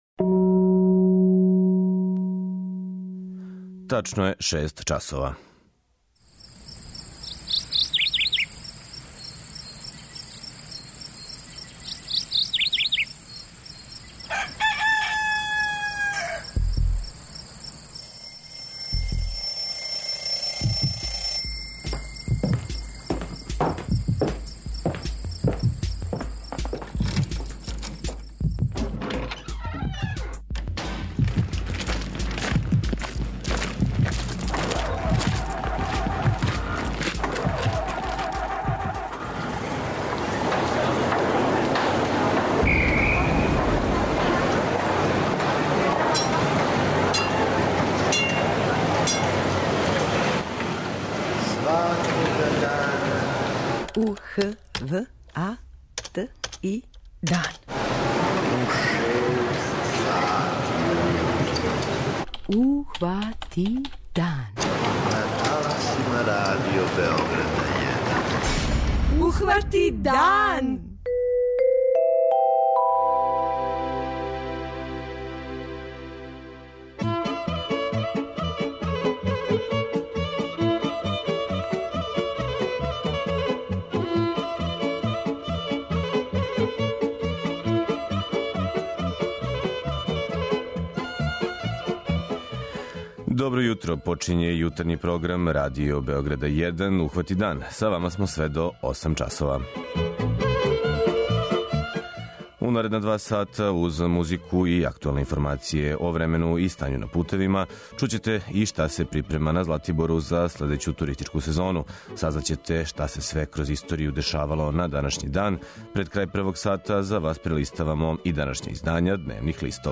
преузми : 57.27 MB Ухвати дан Autor: Група аутора Јутарњи програм Радио Београда 1!